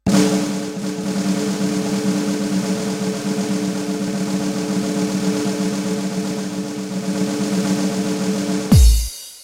Барабанный гром в цирковом шоу